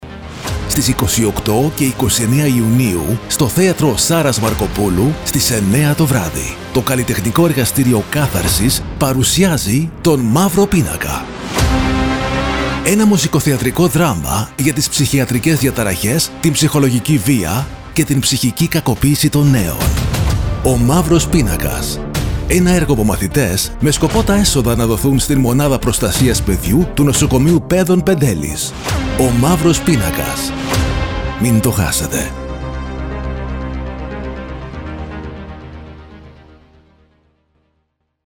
Το ραδιοφωνικό σποτ:
Ο-ΜΑΥΡΟΣ-ΠΙΝΑΚΑΣ-PROMO.mp3